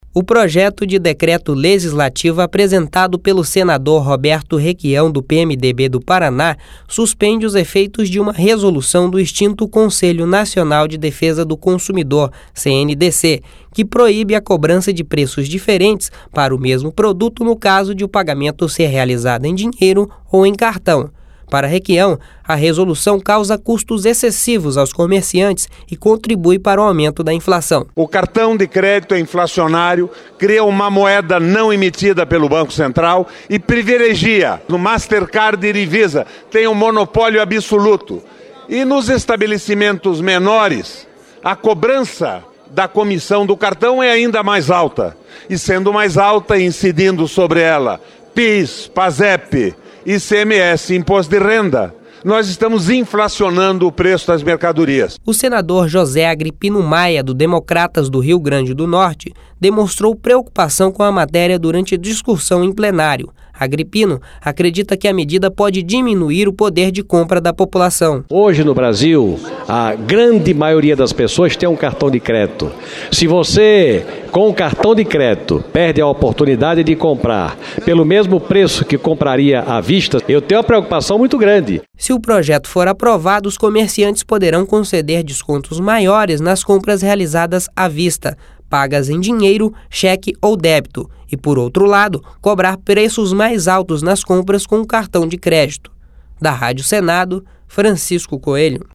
(Repórter) O senador José Agripino Maia, do Democratas do Rio Grande do Norte, demonstrou preocupação com a matéria durante discussão em plenário.